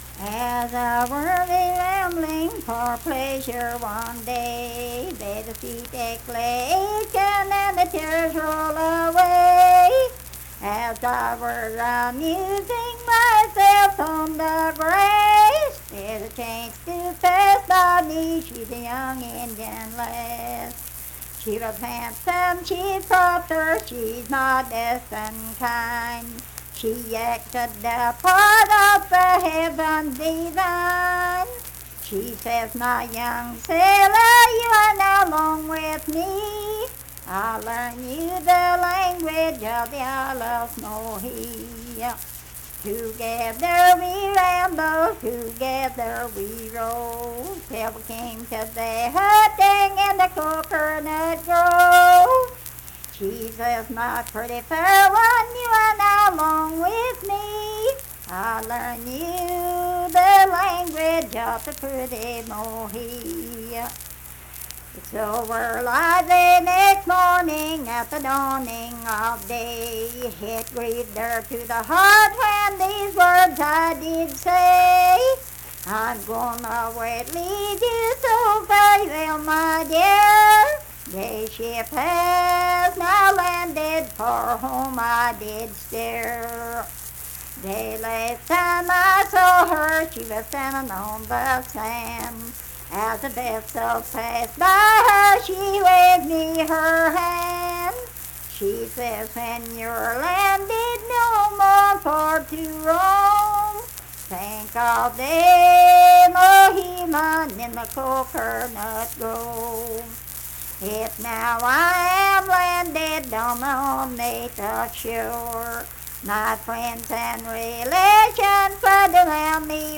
Unaccompanied vocal music performance
Verse-refrain 7(4).
Voice (sung)